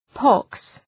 Προφορά
{pɒks}